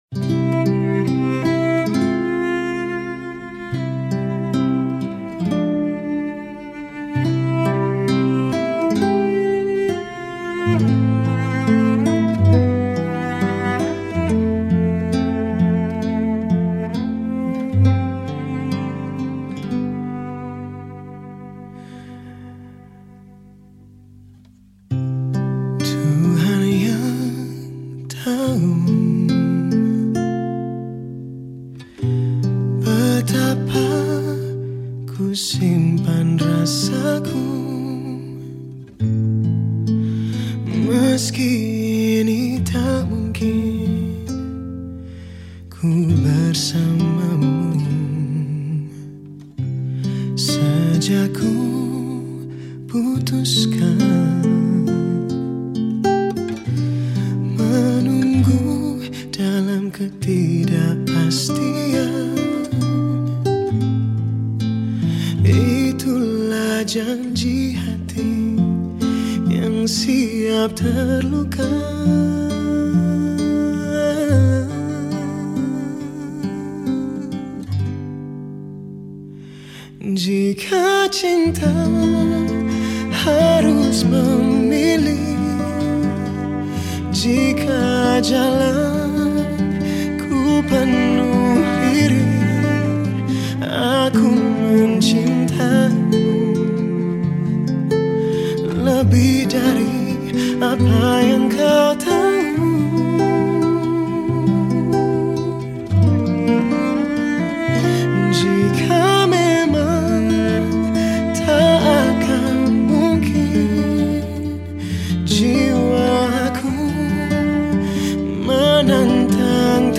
Genre Musik                                 : Musik Pop
Instrumen                                      : Vokal